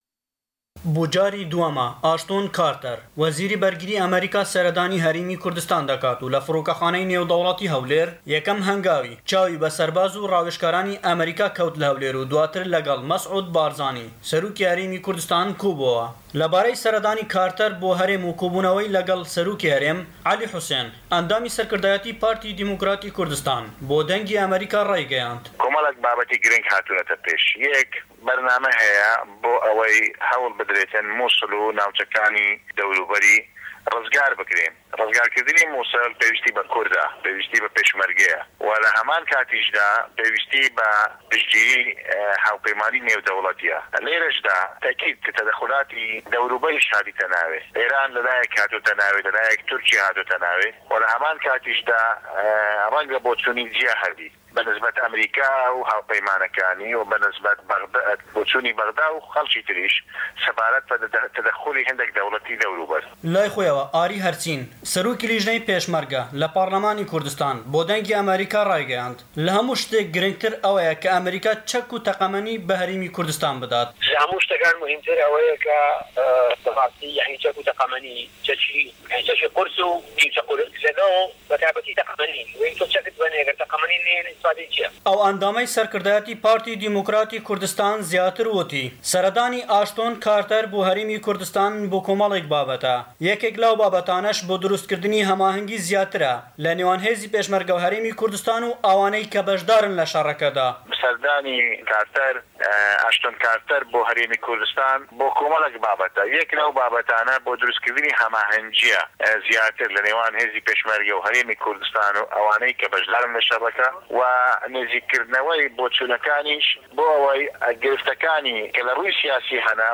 ڕاپـۆرتێـک سەبارەت بە سەردانەکەی ئاشتۆن کارتەر بۆ هەولێر